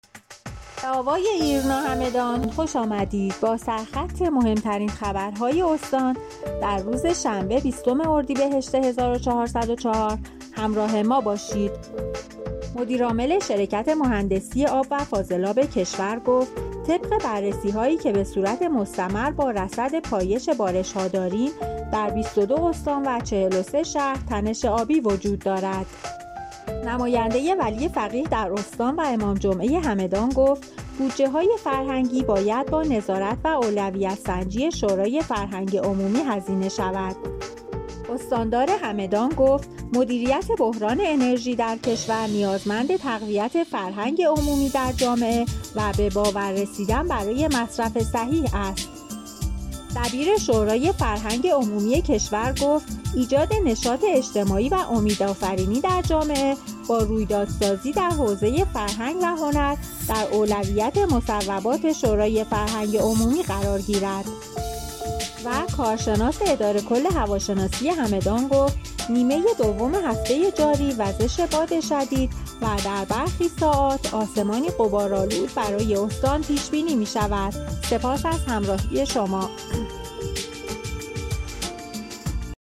همدان-آژانس خبری کارآفرینان اقتصاد- مهم‌ترین عناوین خبری دیار هگمتانه را از بسته خبر صوتی آوای آژانس خبری کارآفرینان اقتصاد همدان دنبال کنید.